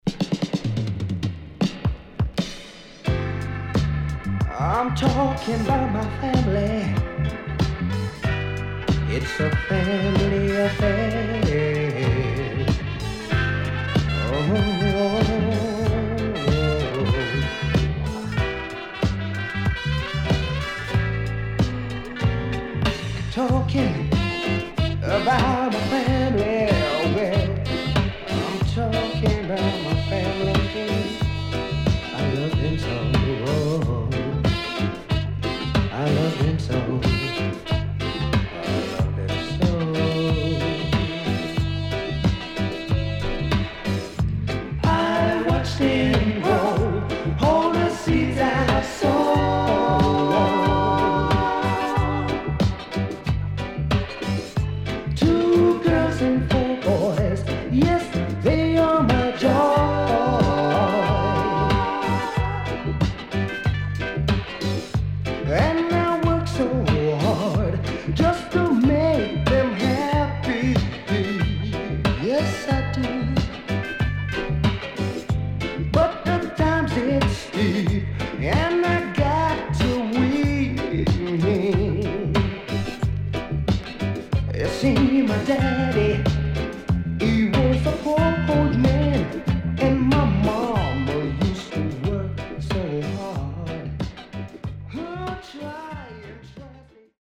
HOME > REISSUE USED [DANCEHALL]